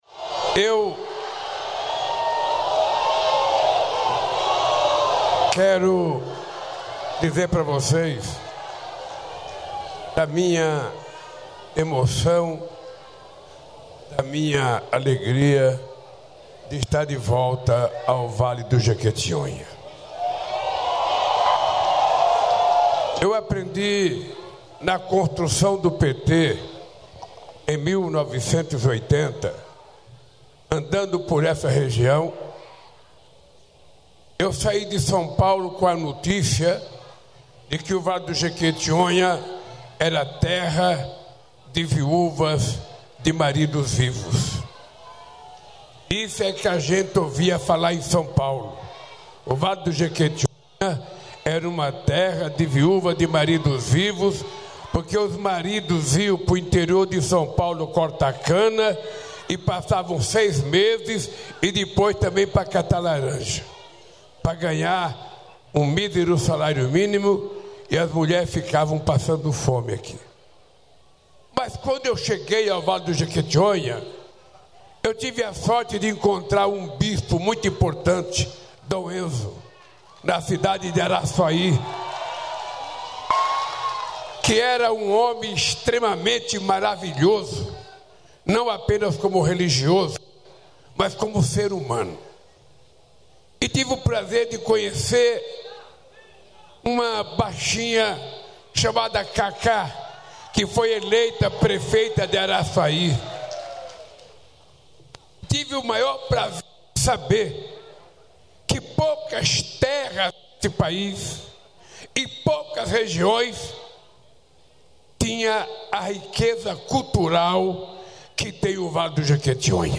Presidente Lula participa de cerimônia no Vale do Jequitinhonha (MG), pela educação nos quilombos
Presidente Lula participa da cerimônia de entregas do governo federal no Vale do Jequitinhonha (MG), nesta quinta-feira (24). O evento compõe o I Encontro Regional de Educação Escolar Quilombola do Sudeste, com anúncios para áreas de educação, igualdade racial, direitos humanos e povos indígenas.